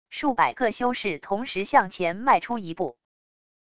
数百个修士同时向前迈出一步wav音频